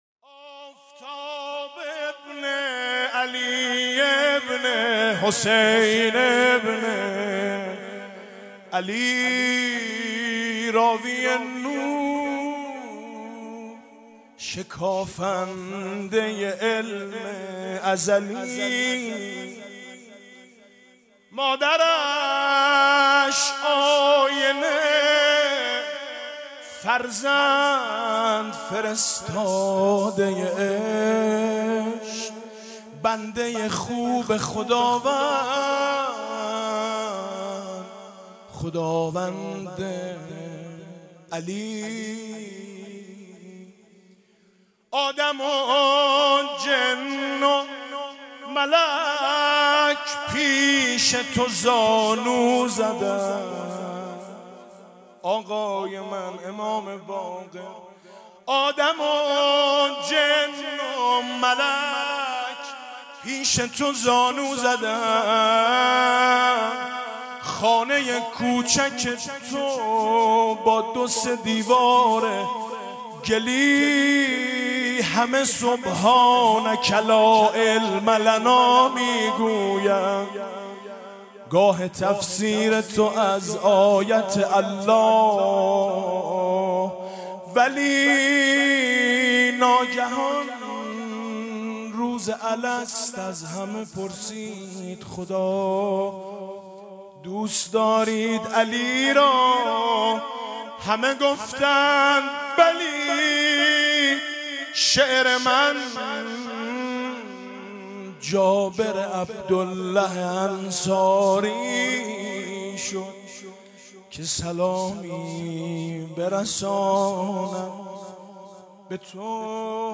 مدح: شعر من جابر بن عبدالله انصاری شد